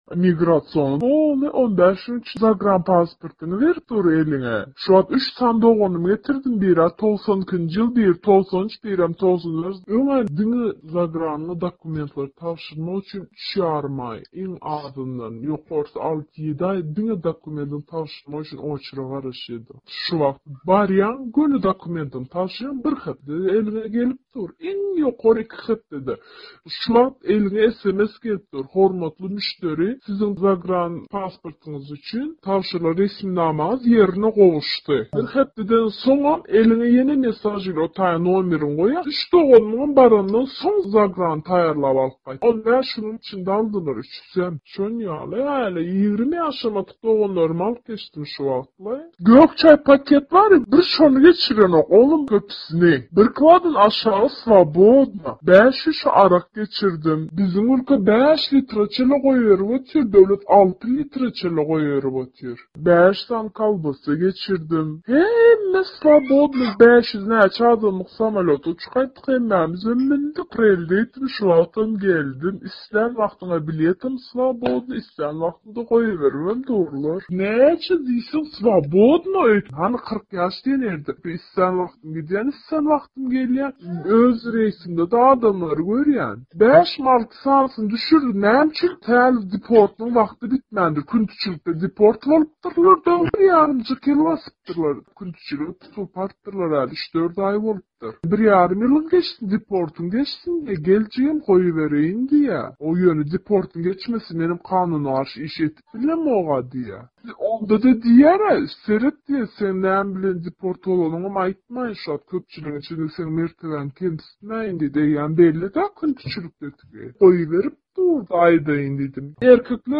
Howpsuzlyk sebäpli adynyň efirde tutulmazlygyny sorap, sesiniň bolsa üýtgedilen görnüşde efirde berilmegini islän, ýaňy-ýakynda Türkmenistandan yzyna dolanyp gelen bir türkmen migranty Azatlyk Radiosyna beren gürrüňinde diňe bir aeroportda däl, eýsem welaýatlarda hem migrasiýa gullugynyň aňry gitse 15 güniň içinde daşary ýurt pasportuny taýýarlap berýändigini hem aýdýar.
Türkmen migranty Aşgabadyň aeroportyndaky ýagdaýlar barada gürrüň berýär